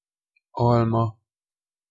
Ääntäminen
Synonyymit tête visage pomme de terre beutschin Ääntäminen France: IPA: [pɔm] Haettu sana löytyi näillä lähdekielillä: ranska Käännös Ääninäyte Substantiivit 1. alma Suku: f .